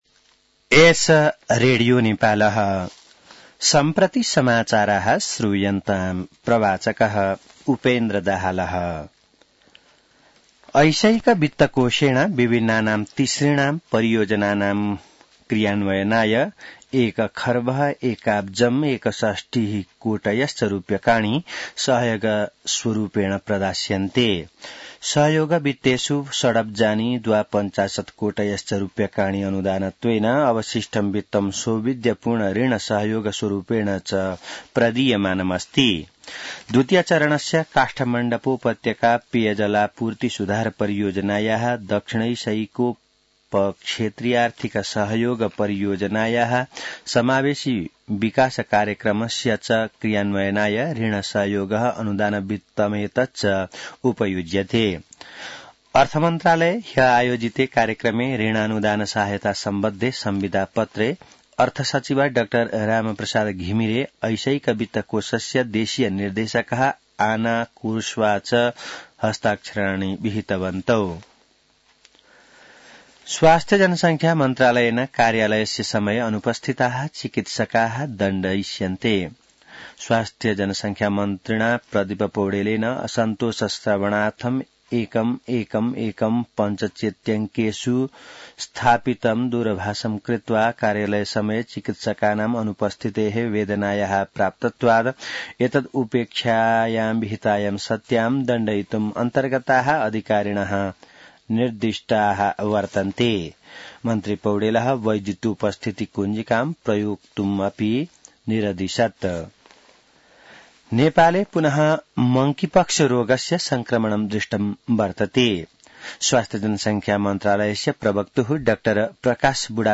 संस्कृत समाचार : ७ पुष , २०८१